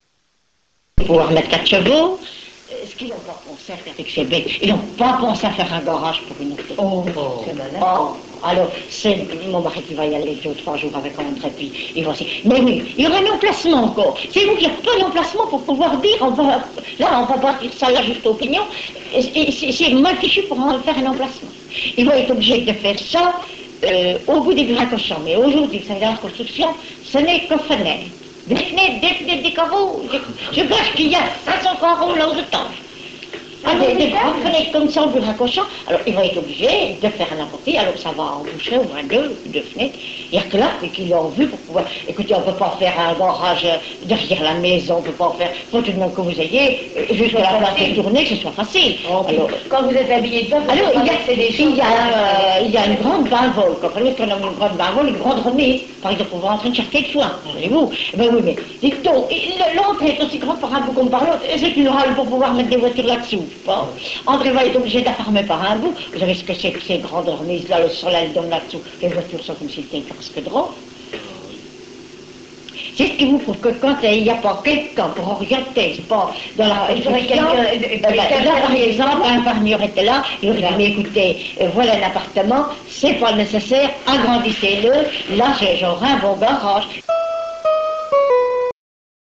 normandie1.mp3